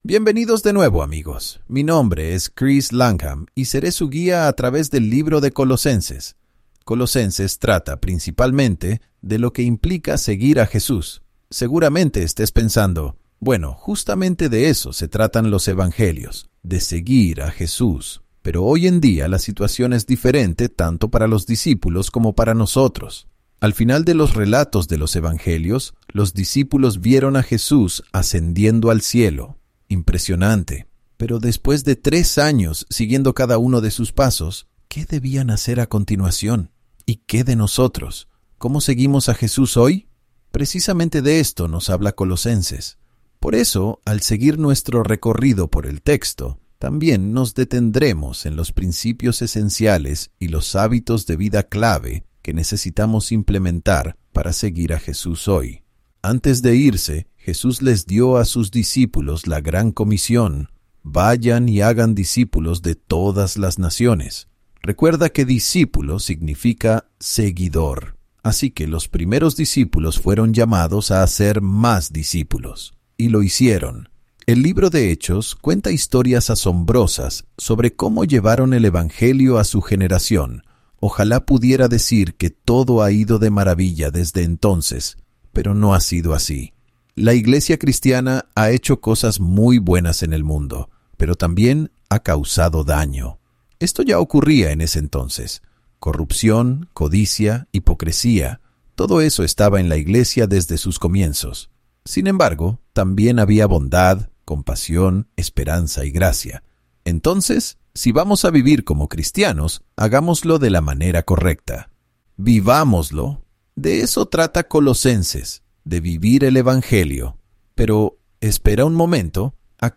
Cada capítulo aporta nuevas perspectivas y comprensión mientras tus maestros favoritos explican el texto y hacen que las historias cobren vida.